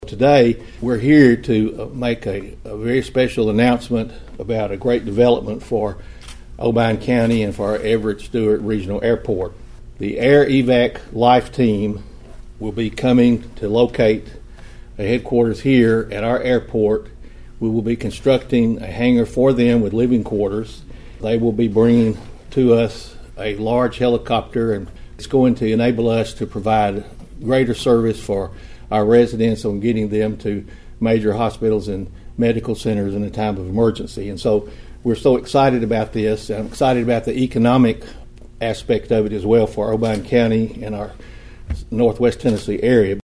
Mayor Steve Carr made the announcement during a press conference at the County Mayor’s Office in Union City.(AUDIO)